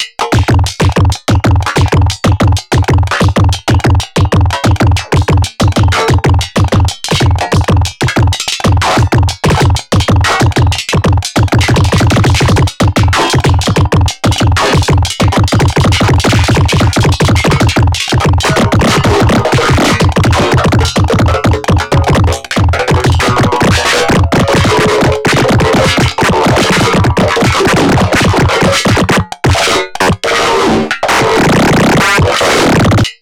Polygrid, custom drums, custom sequencers, custom shit. Can’t find another DAW that let’s you create these typical janky modular rhythms.